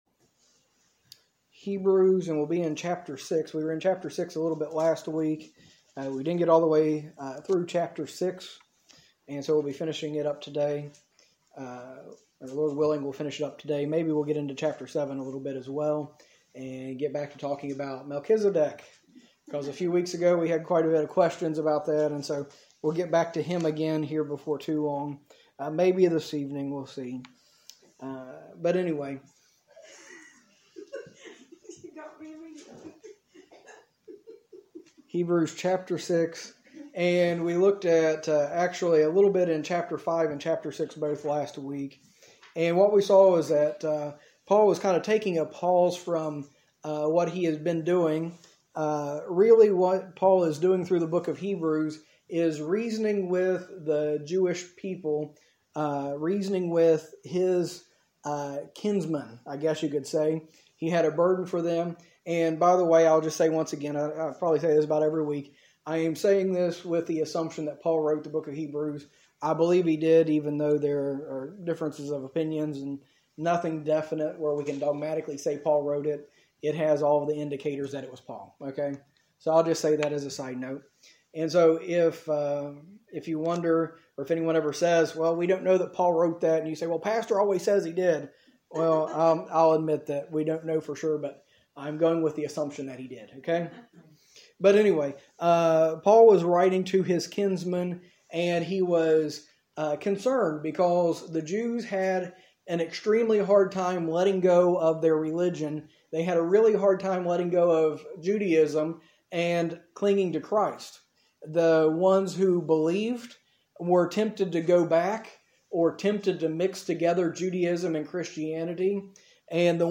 A message from the series "Hebrews."